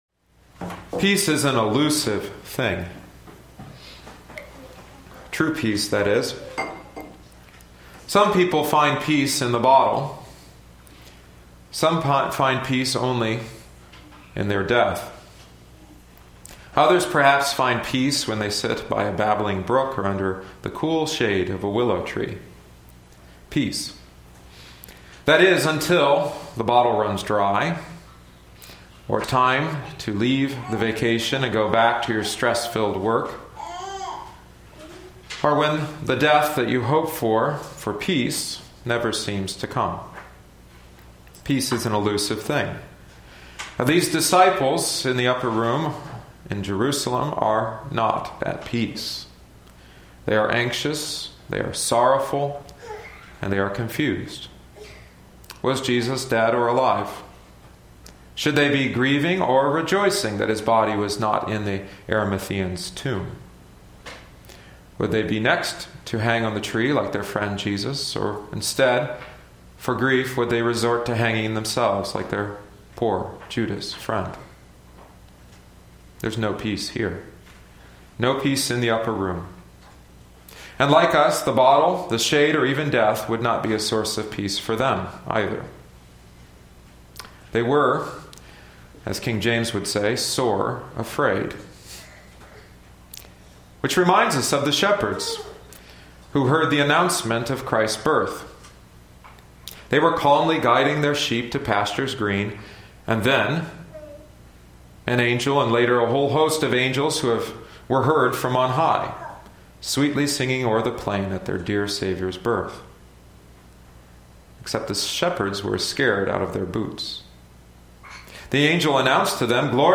in Sermons |